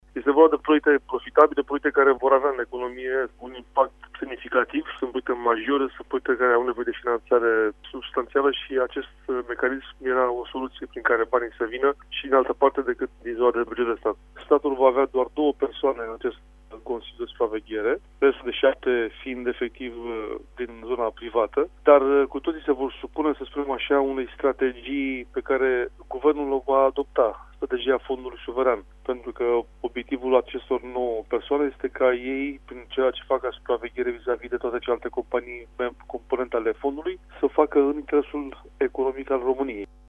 El a precizat că după transferul efectiv al acţiunilor de la marile companii de anul viitor va începe finanţarea marilor proiecte din transporturi şi agricultură: